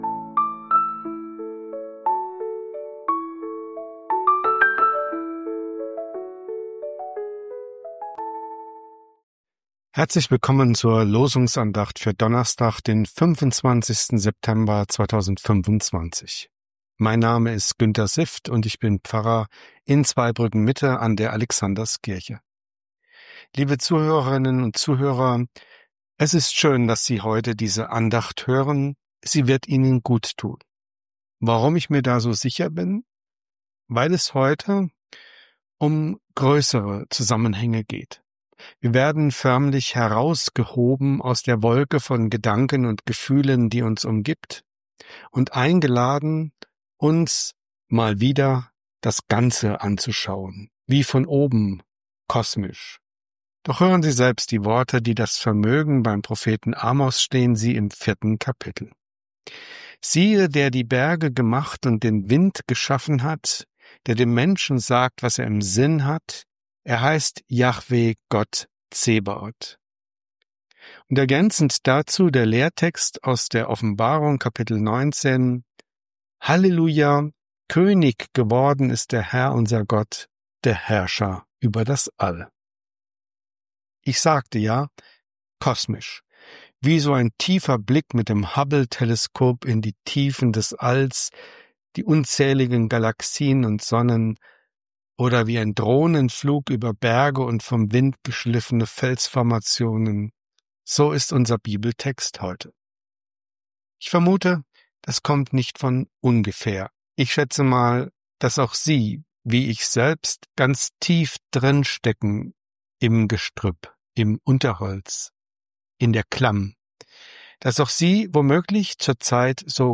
Losungsandacht für Donnerstag, 25.09.2025